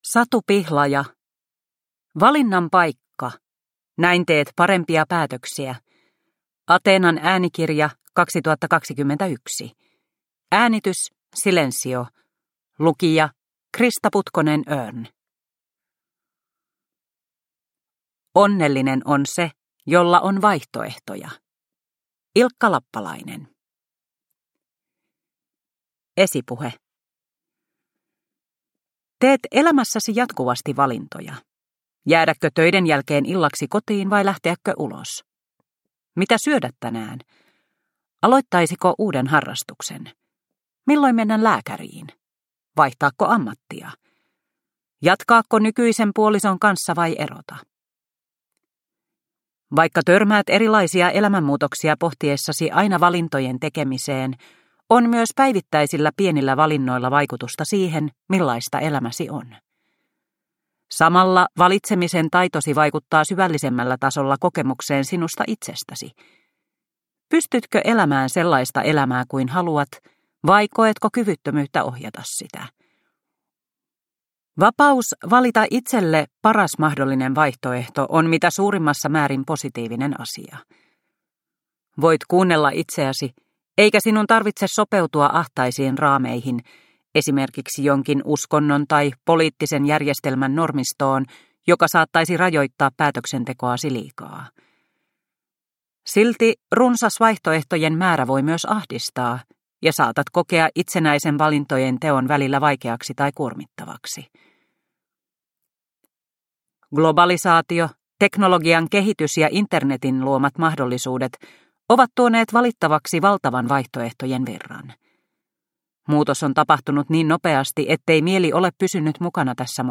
Valinnan paikka – Ljudbok – Laddas ner